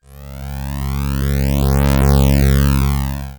Hum15.wav